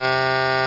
Crumhorn Bass Sound Effect
crumhorn-bass.mp3